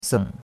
seng3.mp3